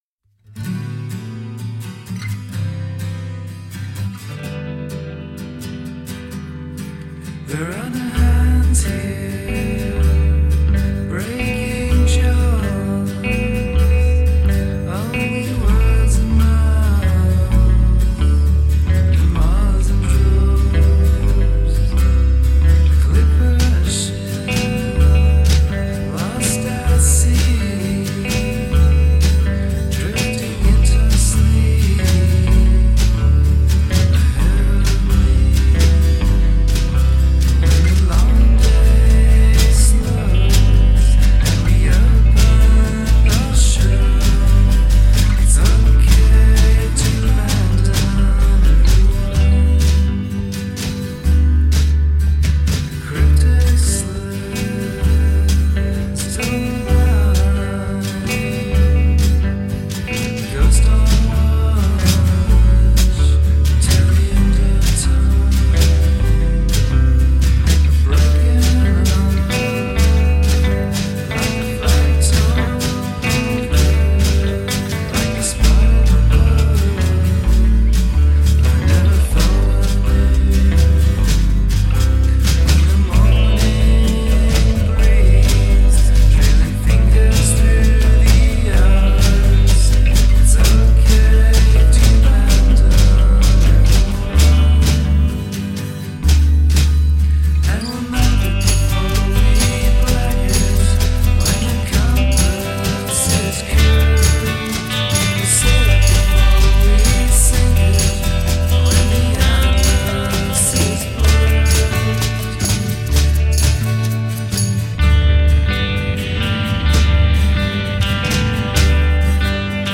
sia verso ballate più acustiche e dilatate